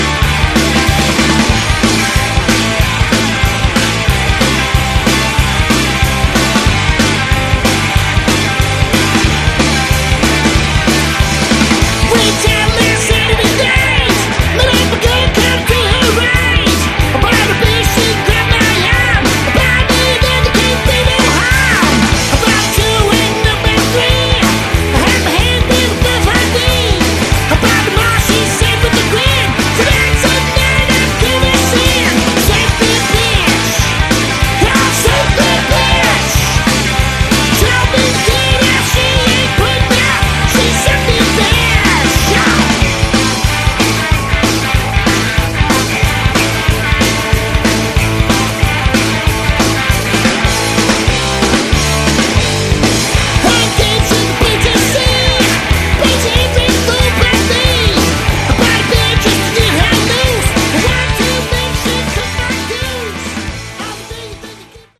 Category: Hard Rock
lead vocals
guitar, vocals, keyboards
bass, vocals
drums, percussion